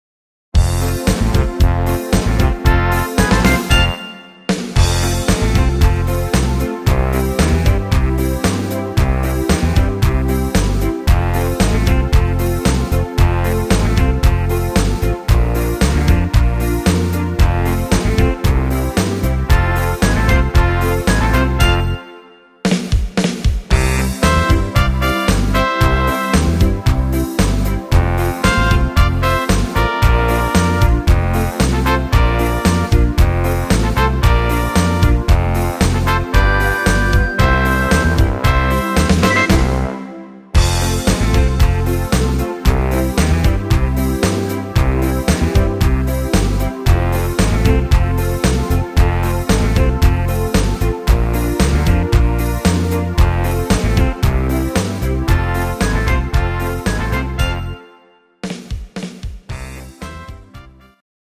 Rhythmus  Foxtrott
Art  Deutsch, Schlager 70er